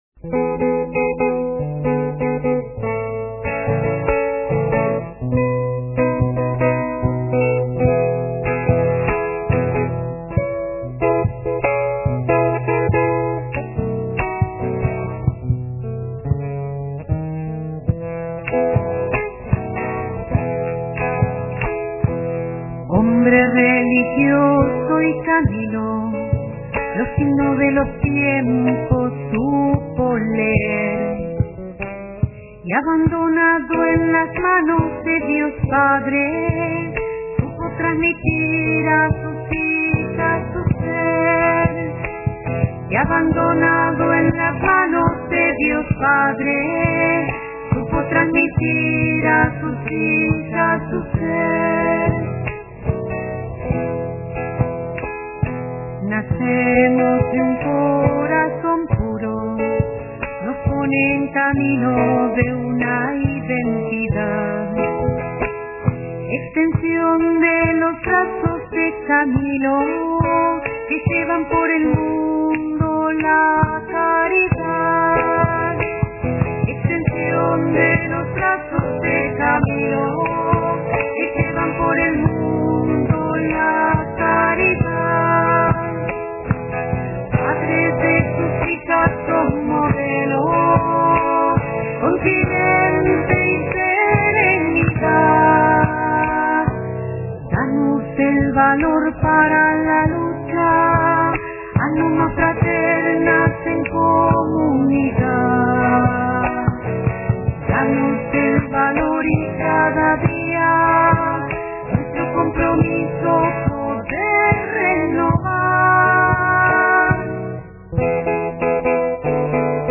Hombre Religioso y Camilo (Zamba)